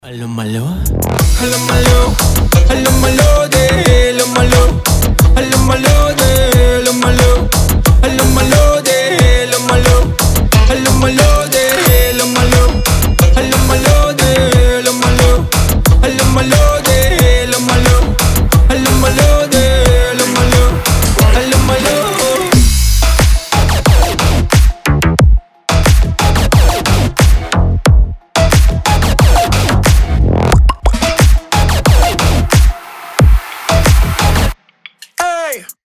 жесткие
мощные басы
Bass House
взрывные
G-House